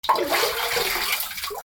水の音
『チャラ』